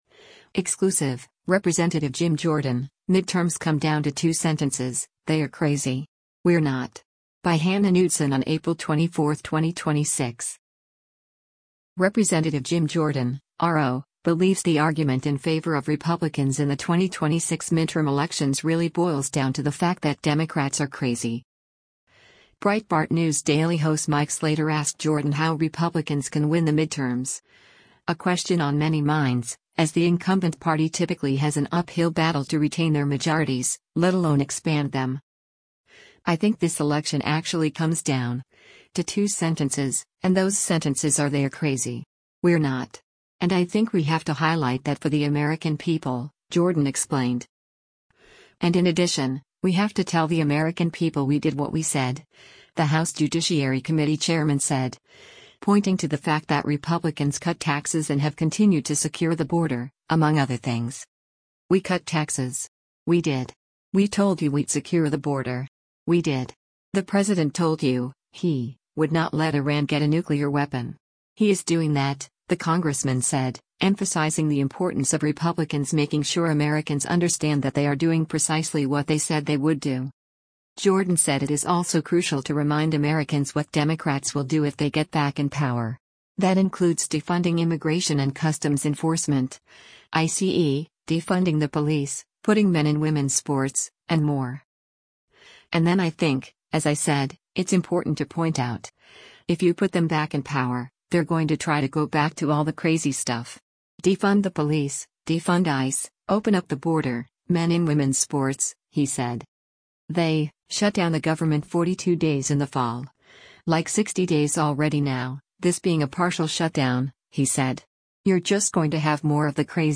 Breitbart News Daily airs on SiriusXM Patriot 125 from 6:00 a.m. to 9:00 a.m. Eastern.